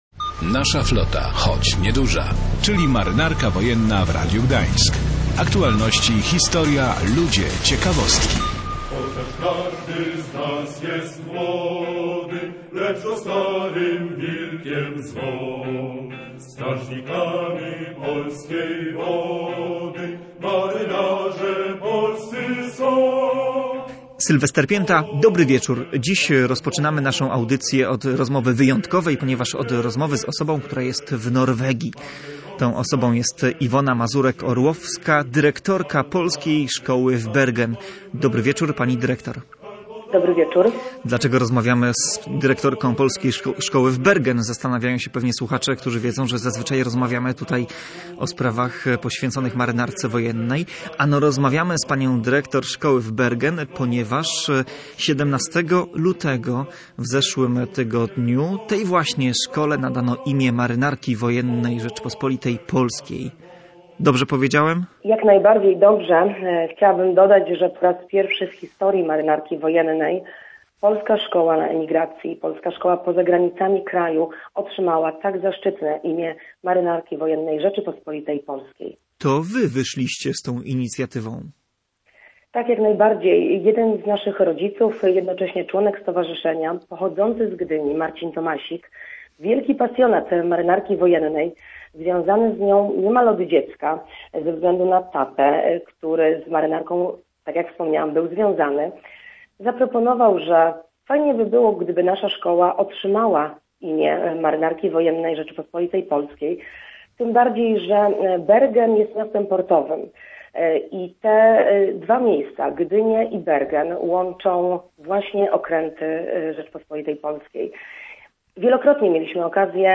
W tej audycji rozmowa
A na koniec, jak zawsze, fragment z naszego radiowego archiwum i dziś pierwsza część rozmowy